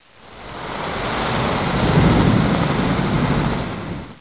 00594_Sound_cloudburst.mp3